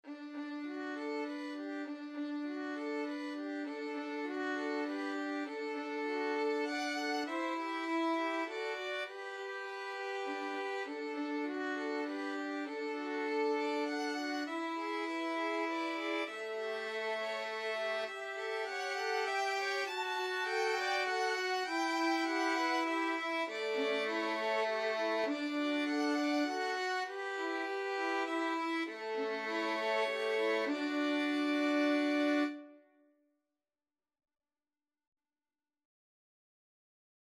Free Sheet music for Violin Trio
Violin 1Violin 2Violin 3
17th-century English folk song.
D major (Sounding Pitch) (View more D major Music for Violin Trio )
3/4 (View more 3/4 Music)
Moderato